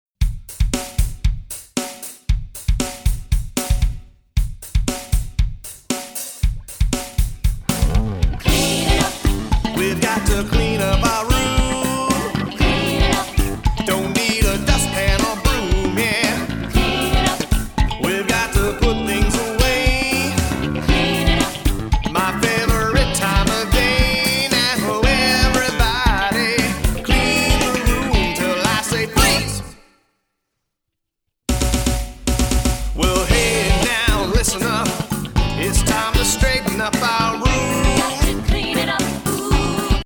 Filled from beginning to end with rockin’ movement tunes